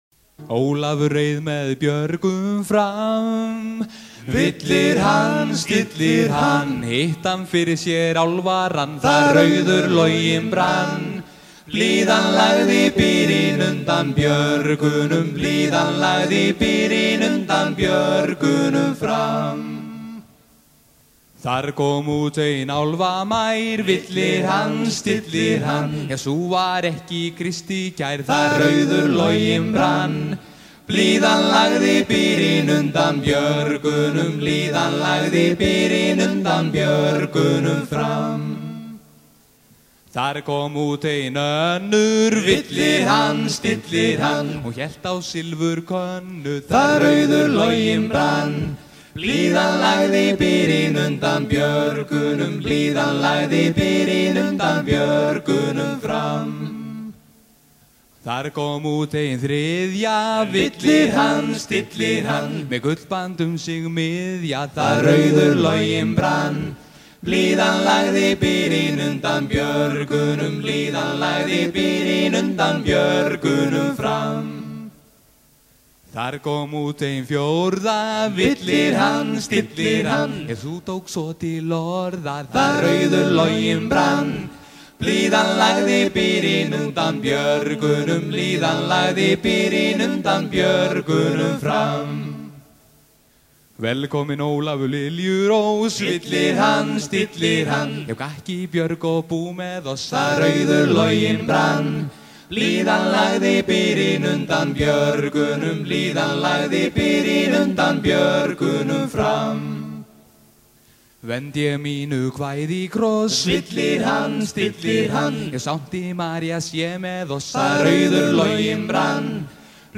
Chant Islandais
Chanson du XIIe siècle
Paimpol 1989